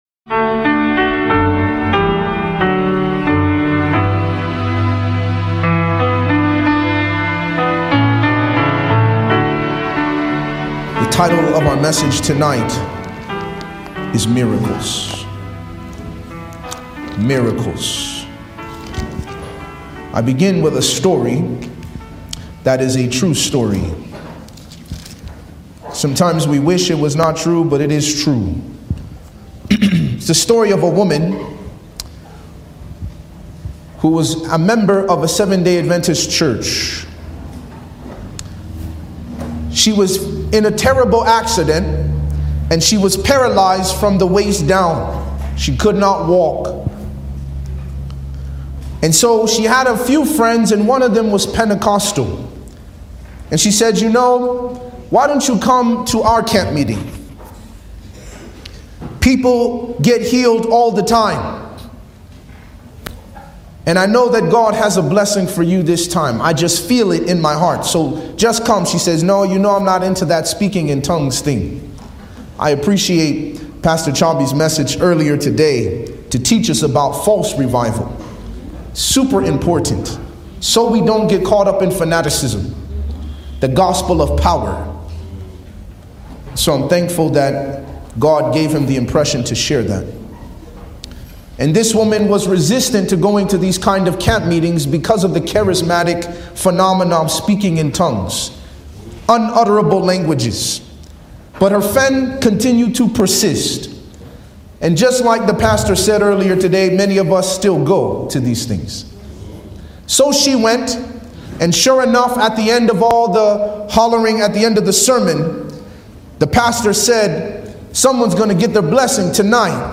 Explore the profound insights on miracles, faith, and spiritual transformation in this thought-provoking sermon. From discerning the source of miracles to embracing faith as a catalyst for change, this message challenges you to reflect on your beliefs and take steps toward a deeper connection with God.